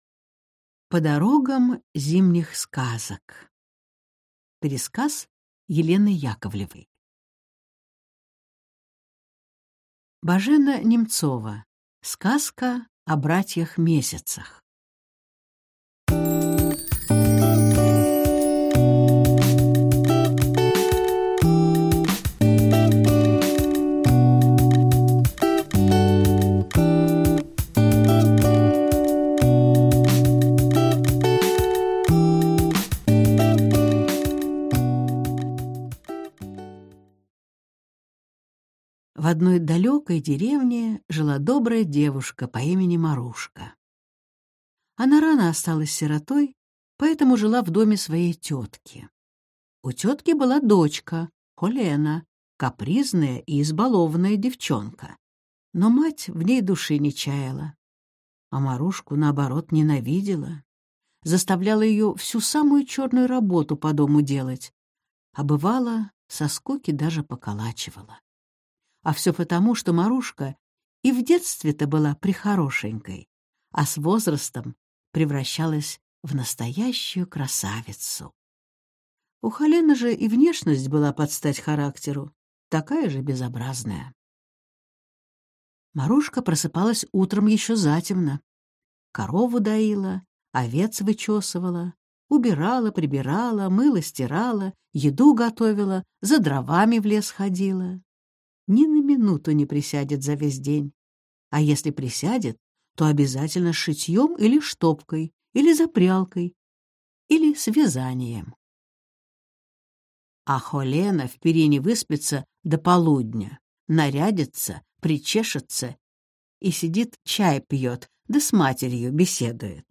Аудиокнига По дорогам зимних сказок | Библиотека аудиокниг